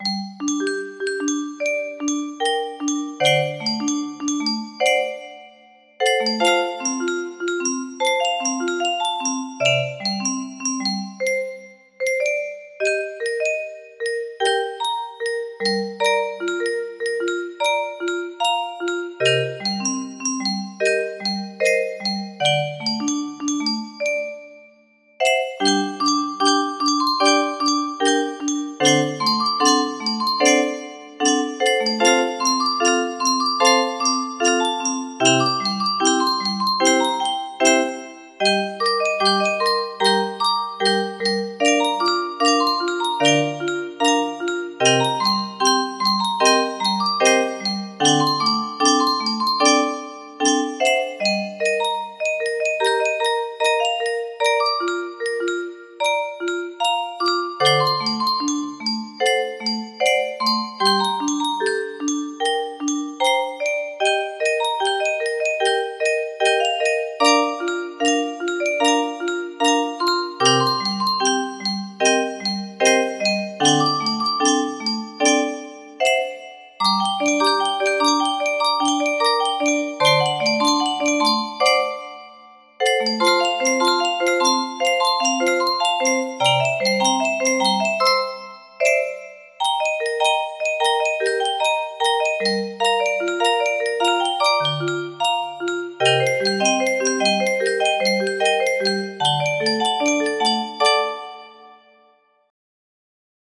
with more rythm ( second verse ) and a solo part